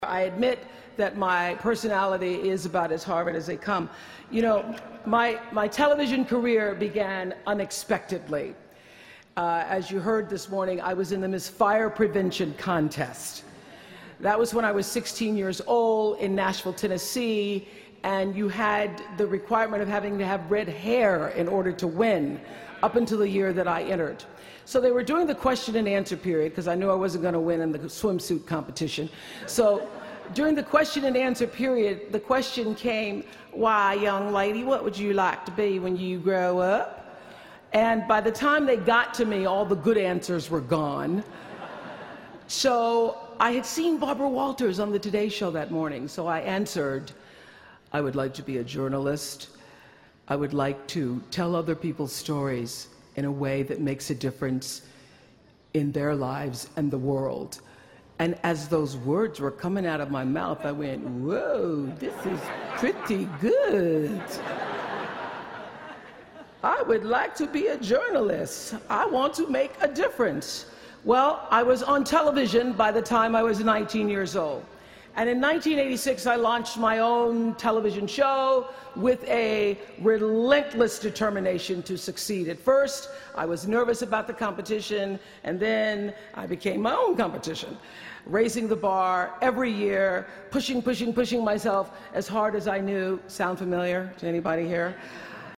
公众人物毕业演讲第352期:奥普拉2013在哈佛大学(3) 听力文件下载—在线英语听力室